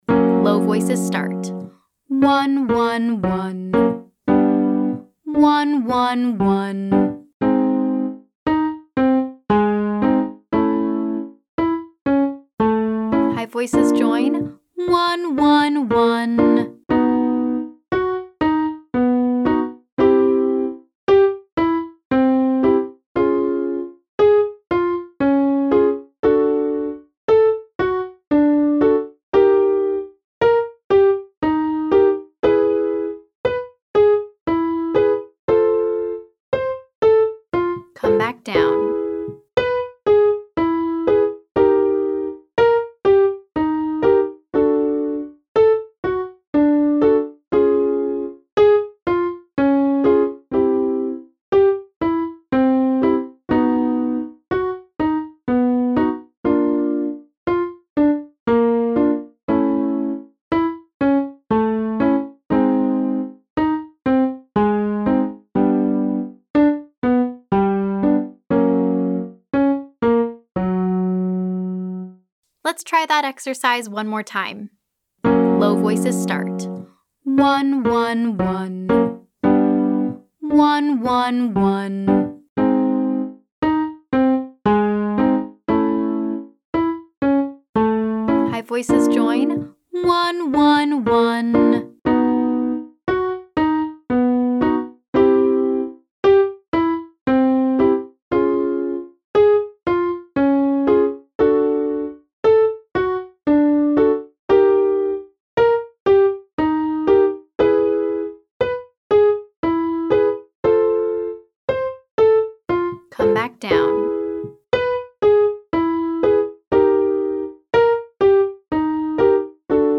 Exercises for day 4: